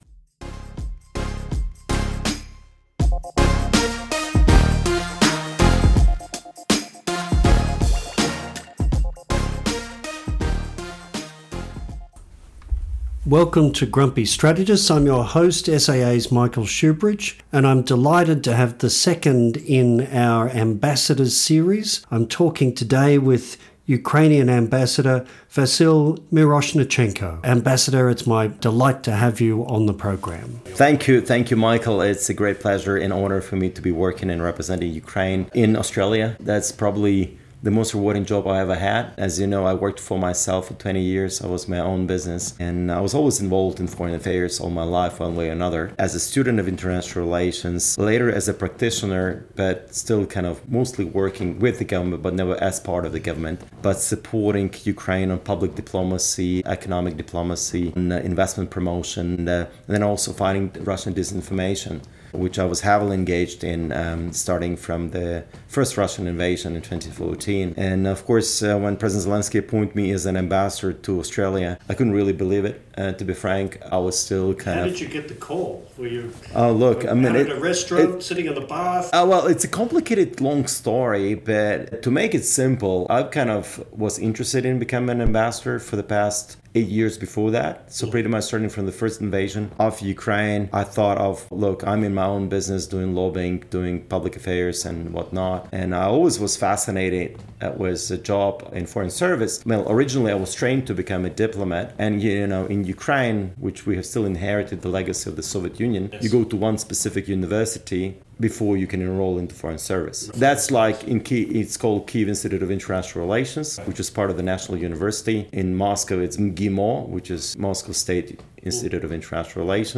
The Ambassadors Series - a Grumpy Strategist meets a high energy Ukrainian Ambassador